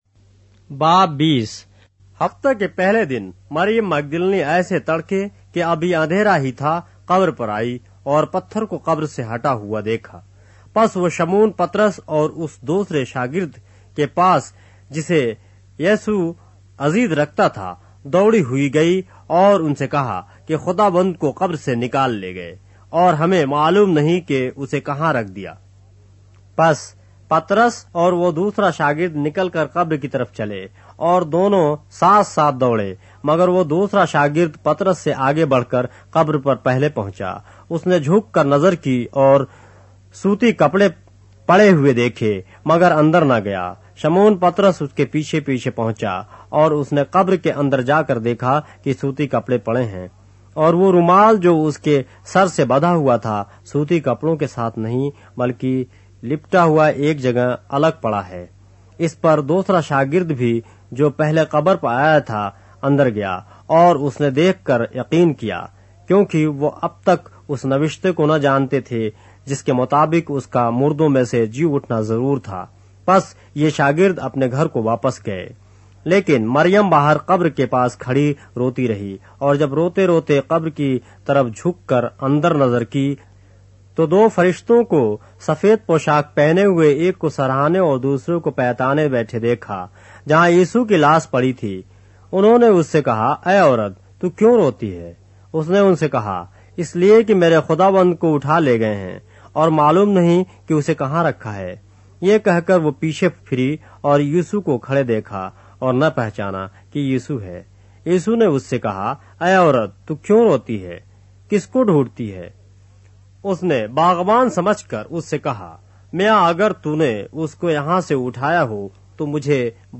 اردو بائبل کے باب - آڈیو روایت کے ساتھ - John, chapter 20 of the Holy Bible in Urdu